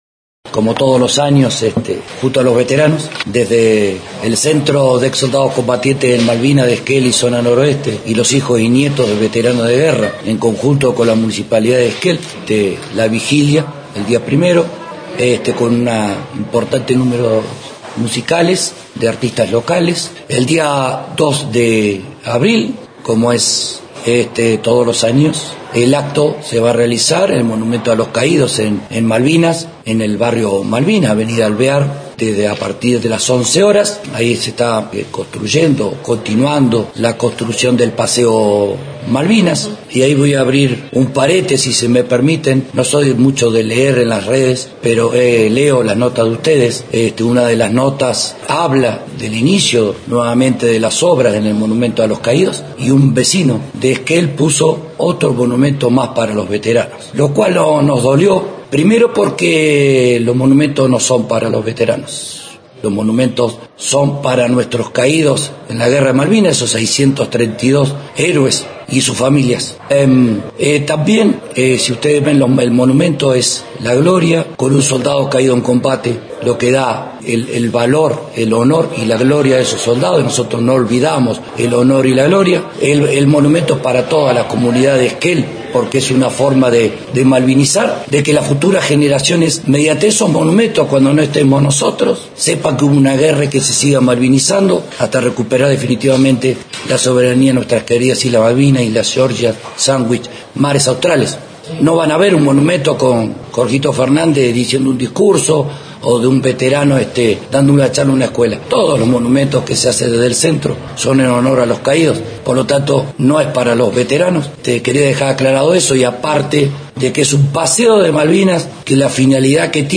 En el Centro Cultural Melipal se hizo una conferencia de prensa para anunciar las actividades en marcadas en el 2 de Abril «Día del Veterano y los Caídos en la Guerra de las Malvinas«. Habrá una Vigilia en el Gimnasio Municipal, se hará el acto oficial y una muestra de fotos y objetos.